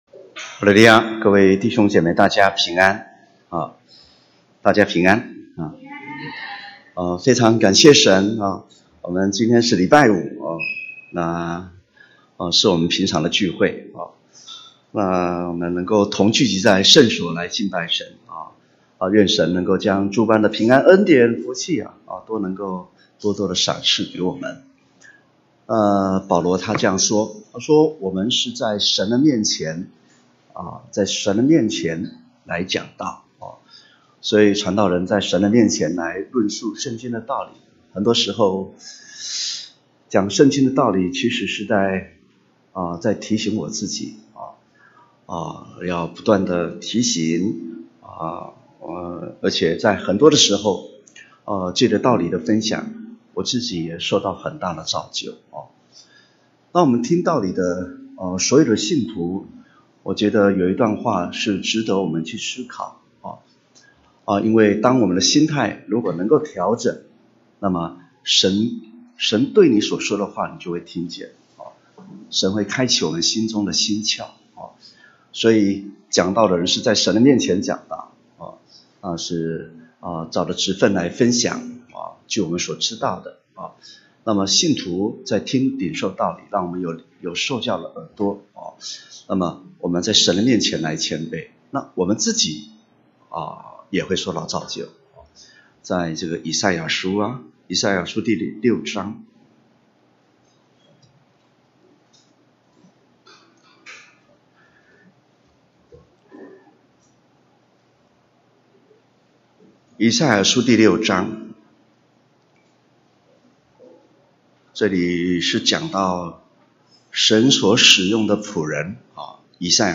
2018年3月份講道錄音已全部上線